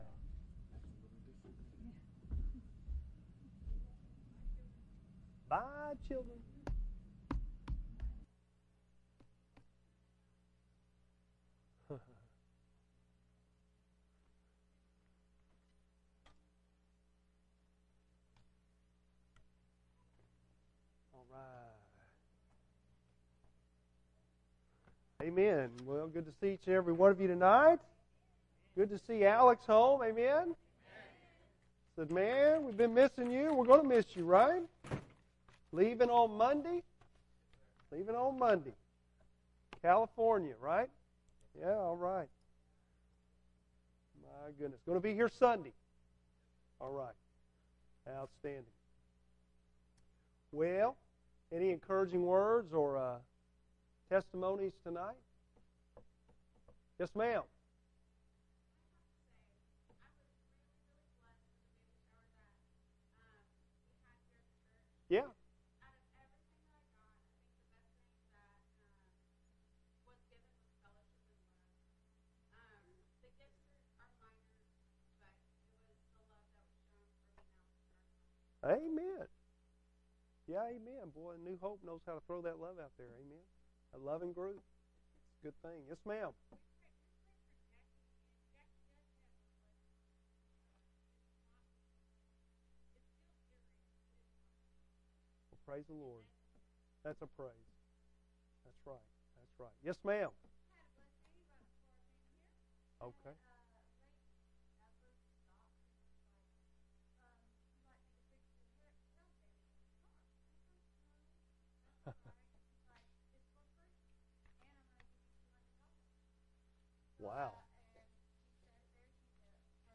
Bible Text: Genesis 32:1-12 | Preacher